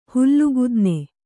♪ hullu gudne